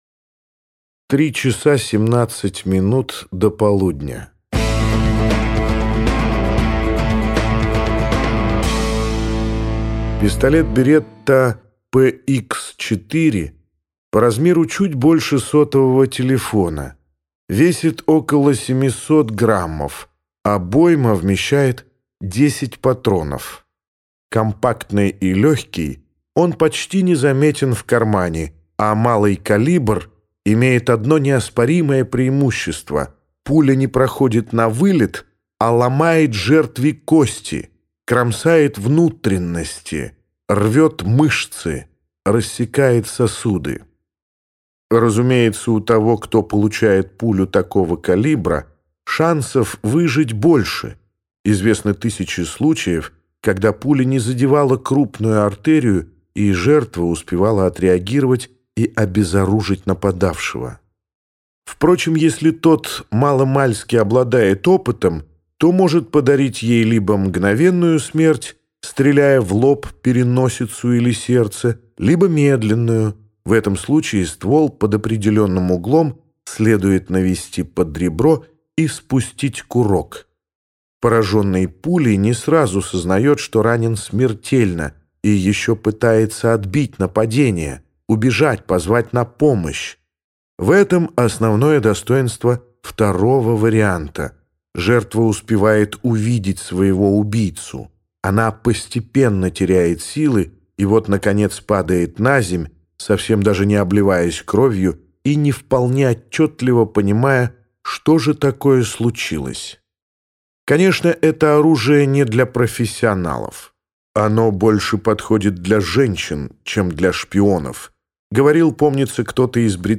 Аудиокнига Победитель остается один - купить, скачать и слушать онлайн | КнигоПоиск